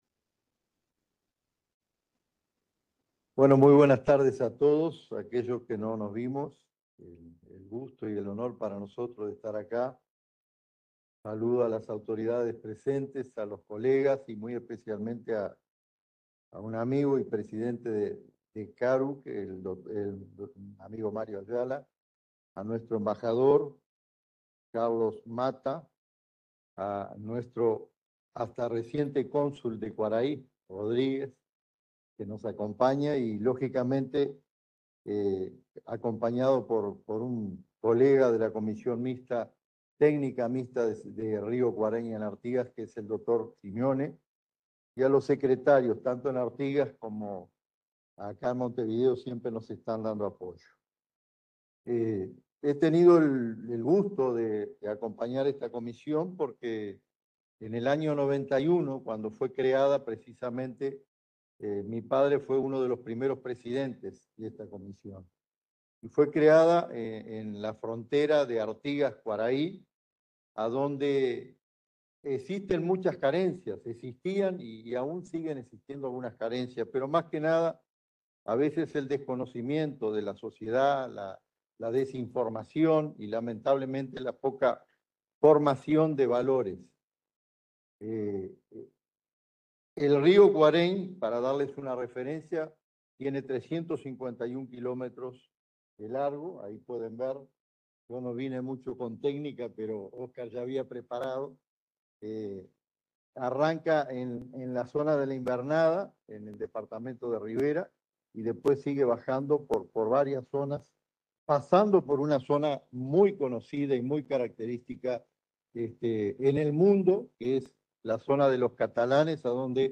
El pasado 6 de noviembre se desarrolló en Montevideo el II Encuentro de organismos de gestión de recursos hídricos transfronterizos de la Cuenca del Plata, evento que tuvo lugar en la sede del Banco de Desarrollo de América Latina y el Caribe (CAF).
II-EOGRHT-CdP-Panel-5-Baja-audio-extractor-mp3cut.net_.mp3